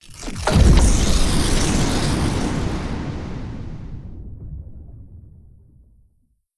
AlternateTimelineTearSFX.wav